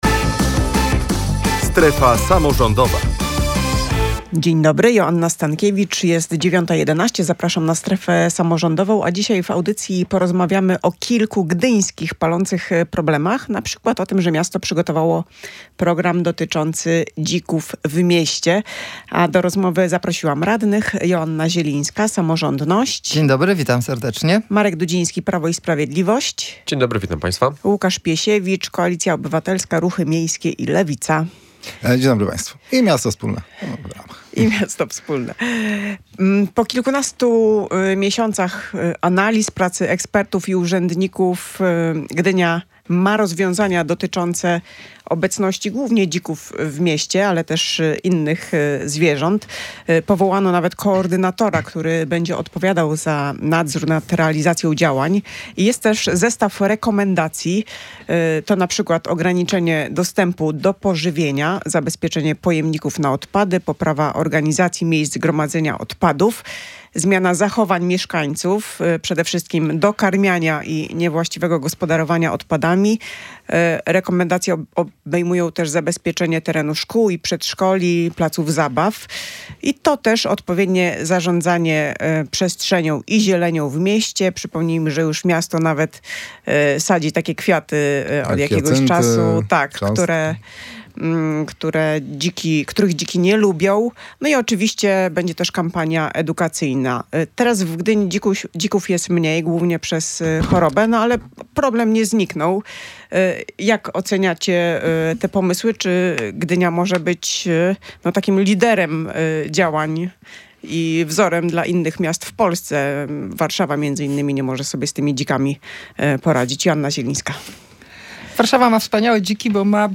Jak rozwiązać problem dzików w Gdyni? Posłuchaj dyskusji radnych